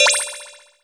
晋级下一关音效.mp3